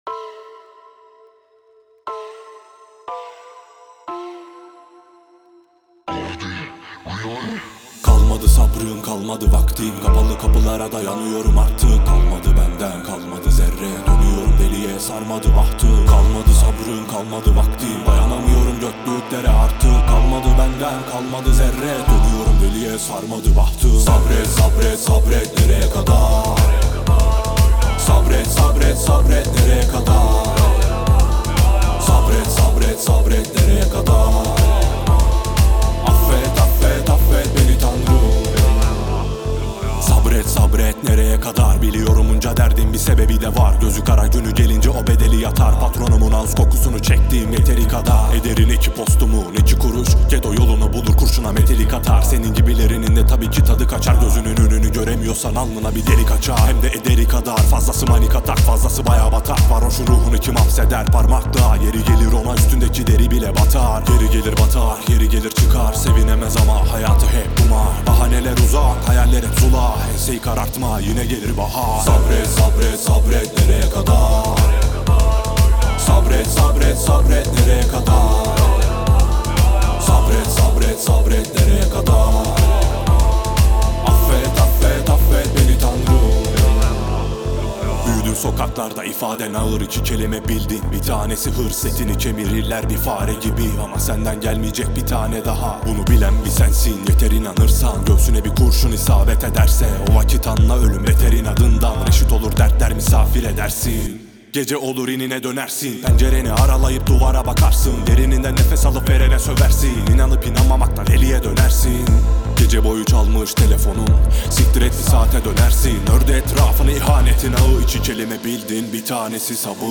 Трек размещён в разделе Турецкая музыка / Рэп и хип-хоп.